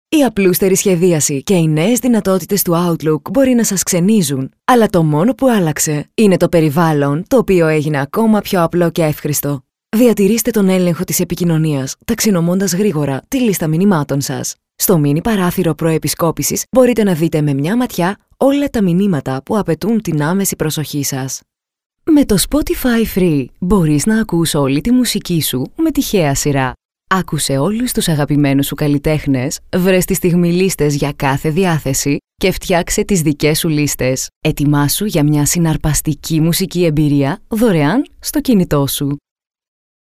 Professionelle Sprecher und Sprecherinnen
Weiblich